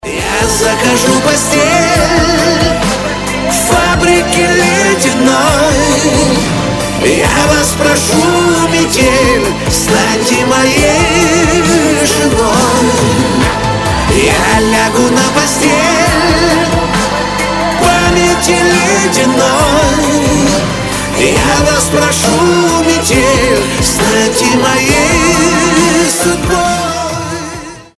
• Качество: 320, Stereo
мужской вокал
громкие
эстрадные